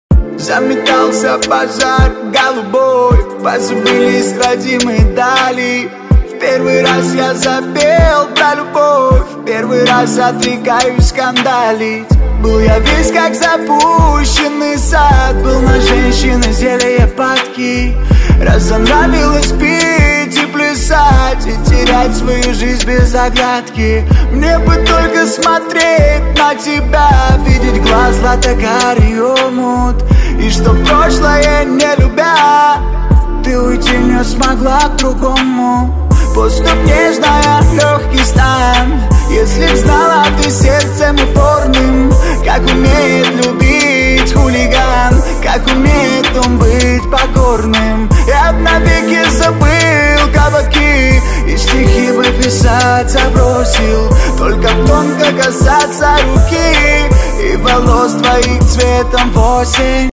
• Качество: 128, Stereo
лирика
русский рэп
романтичные